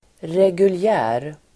Uttal: [regulj'ä:r]